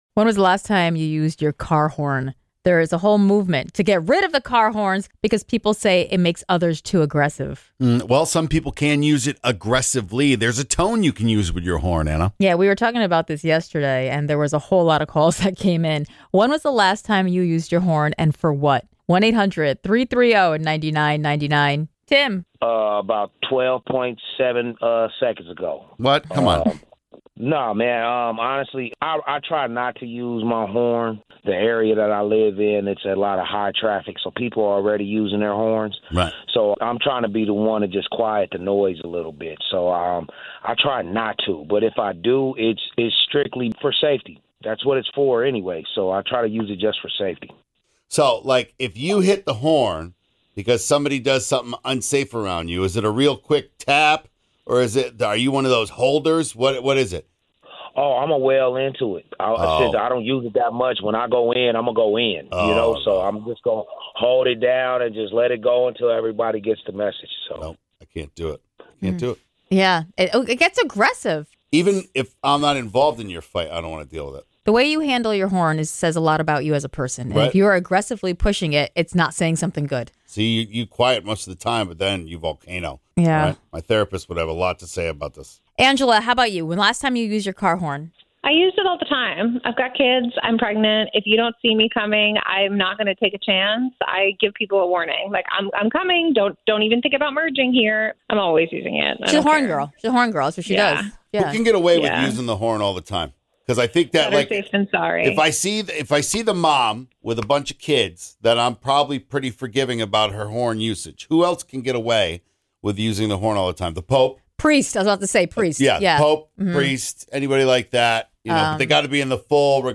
Callers share when they honk their horn!